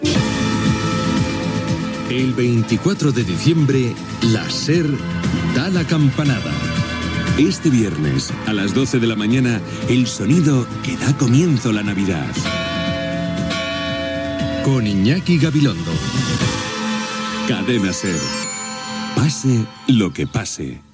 Promoció de l'espai que emetrà les campanades de les dotze del migdia del 24 de desembre des de diferents esglésies i catedrals.